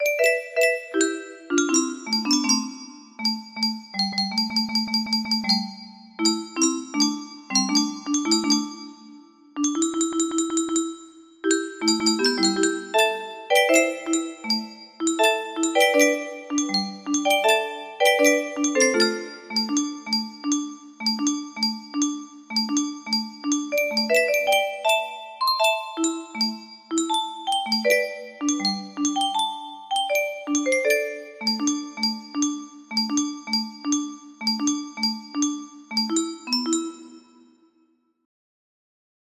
edited to fit 30 note range, so it sounds a bit off